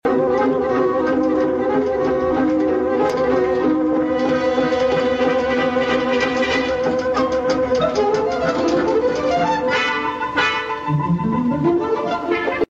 Drilling Exercise .